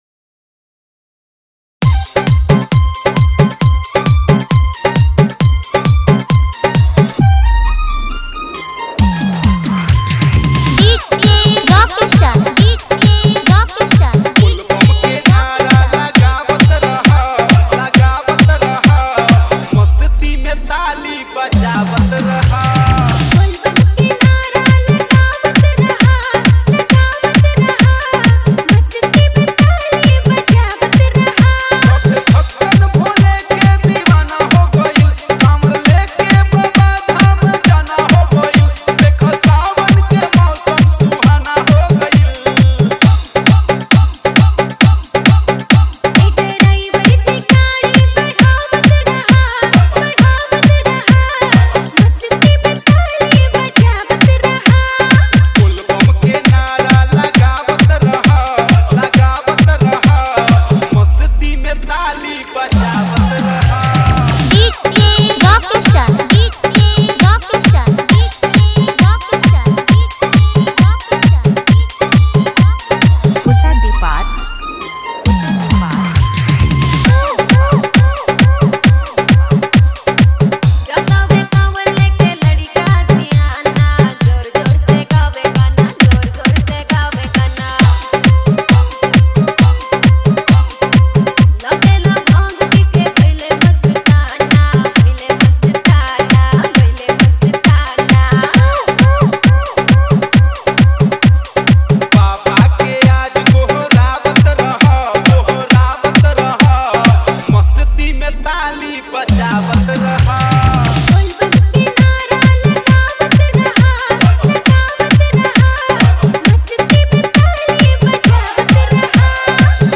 Category:  Bol Bam 2021 Dj Remix Songs